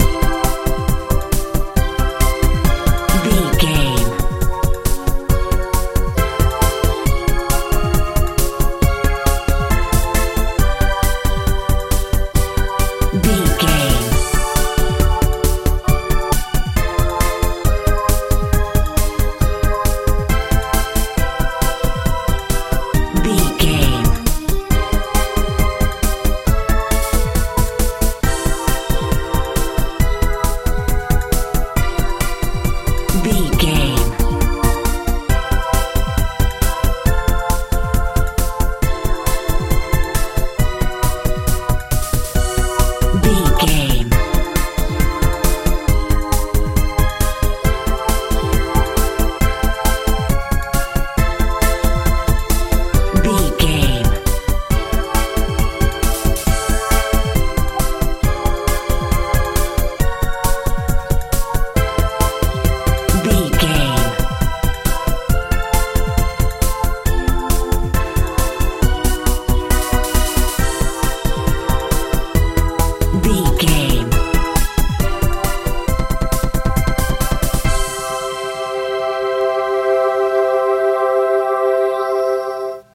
modern dance feel
Ionian/Major
hopeful
joyful
bass guitar
synthesiser
drums
80s
90s
peaceful
playful
cheerful/happy